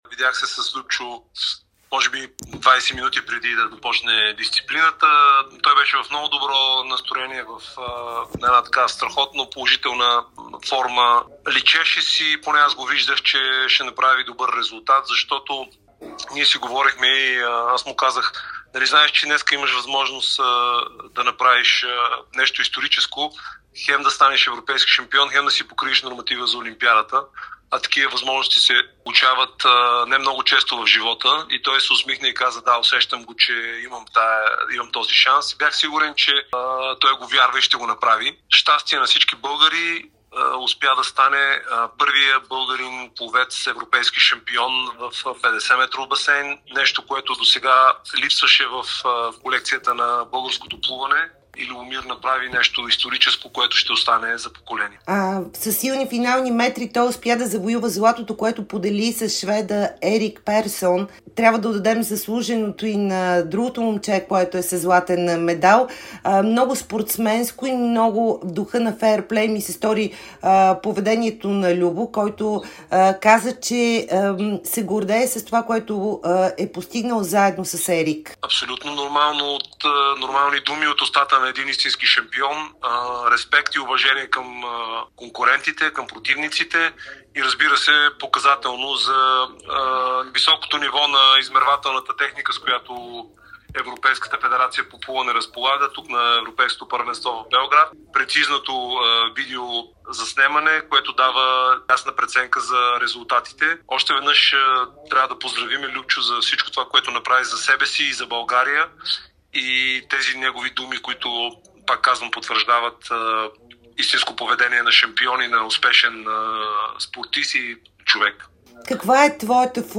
Членът на Техническия комитет към европейската централа по плуване Петър Стойчев говори специално пред Дарик радио и dsport за спечелената европейска титла от Любомир Епитропов. Той сподели, че е разговарял с него преди старта и му е казал, че има възможност за нещо историческо.